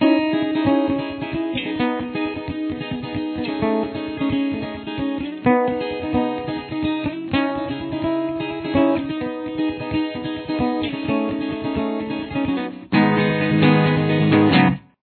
CAPO – Fourth Fret
Bridge